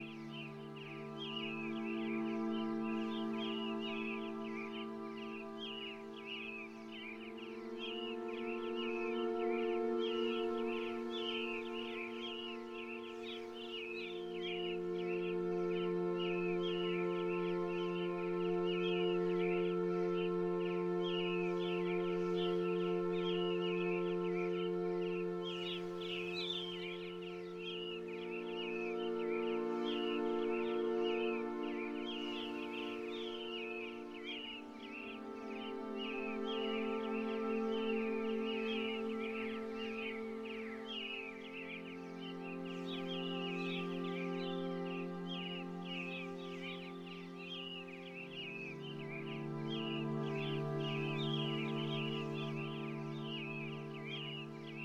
Add ambient music which changes based on pill level